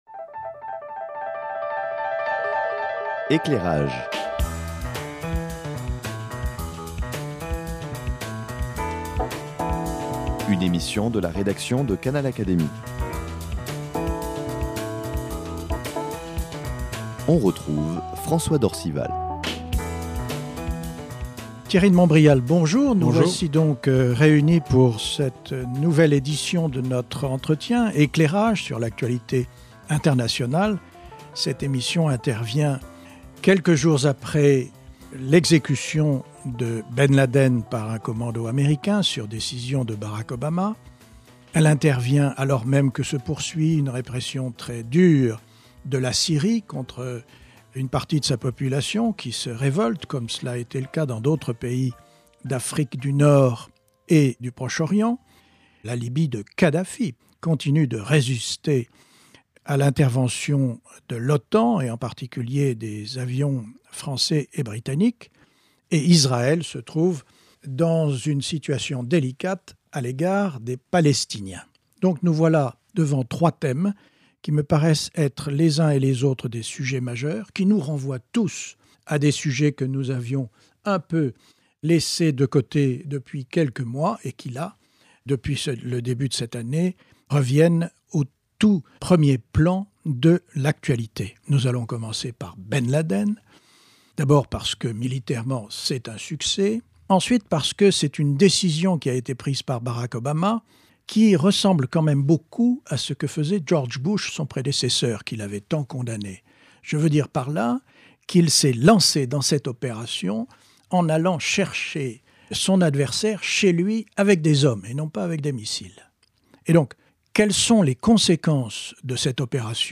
François d’Orcival et Thierry de Montbrial, tous deux confrères de l’Académie des sciences morales et politiques, se retrouvent dans le studio de Canal Académie pour analyser les conséquences d’une succession de faits majeurs : l’exécution de Ben Laden, la poursuite des bombardements aériens en Libye, la répression en Syrie, les initiatives palestiniennes.